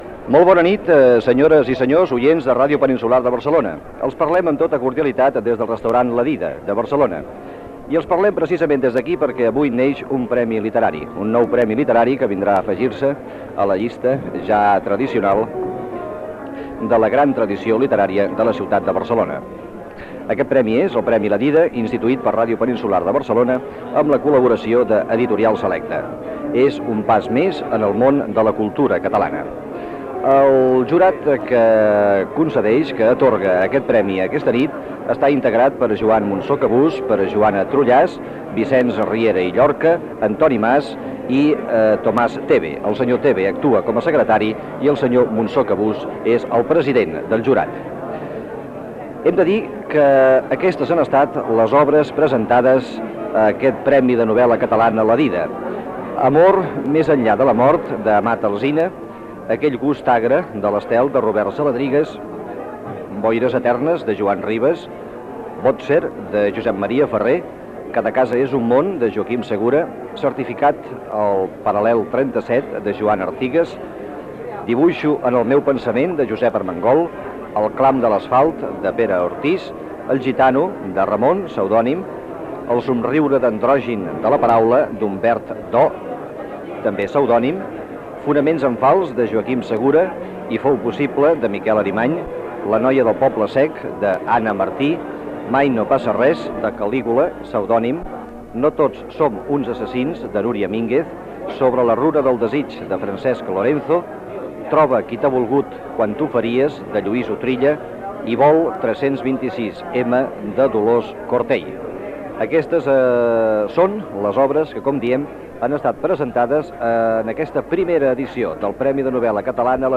Transmissió, des del restaurant la Dida de Barcelona, de la primera edició del Premi de Novel·la Catalana la Dida de Ràdio Peninsular de Barcelona. Integrants del jurat, obres presntades, resultats de les votacions